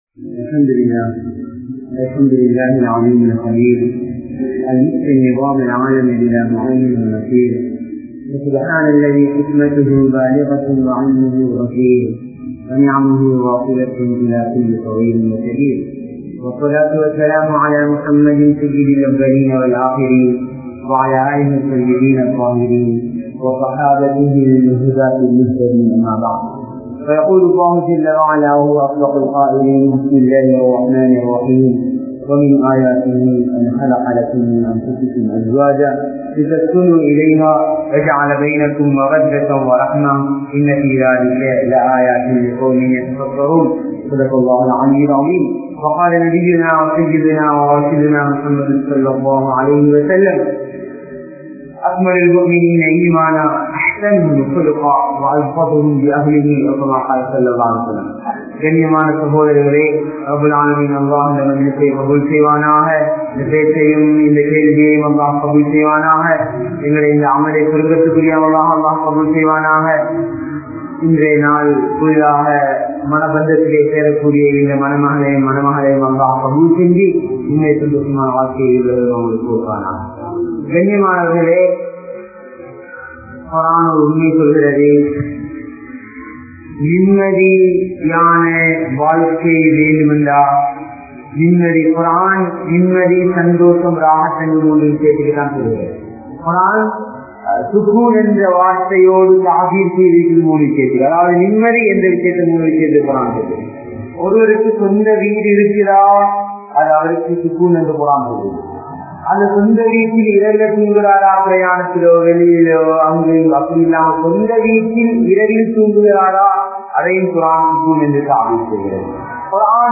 Seeralium Indraya Thirumanagal(சீரழியும் இன்றைய திருமணங்கள்) | Audio Bayans | All Ceylon Muslim Youth Community | Addalaichenai
Colombo 12, Aluthkade, Muhiyadeen Jumua Masjidh